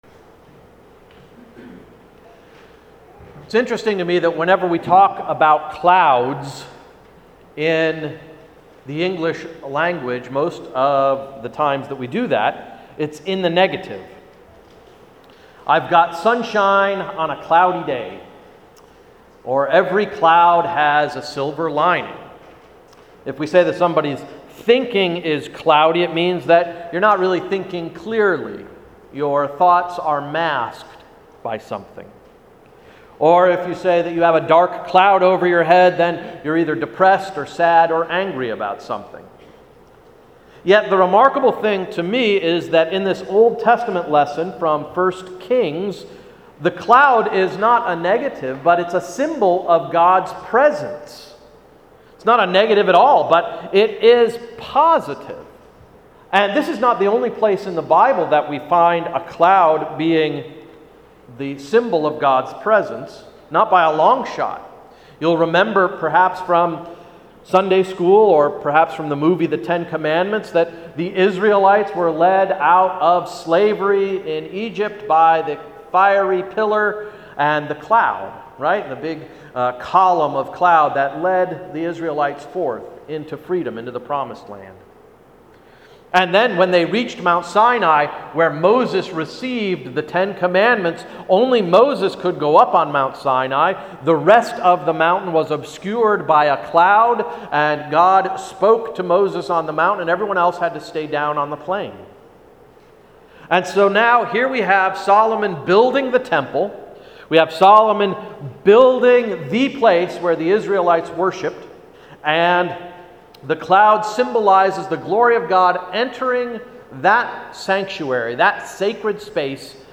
Sermon of August 26, 2012–‘Right Church, Right Pew’